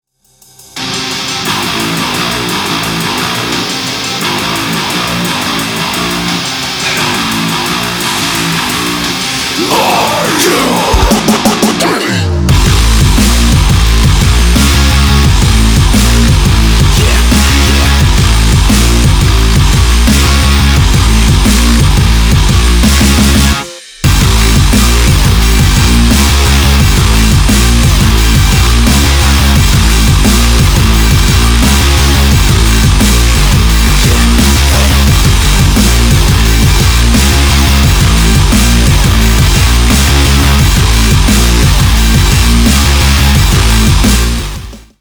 • Качество: 320, Stereo
жесткие
мощные
Electronic
nu metal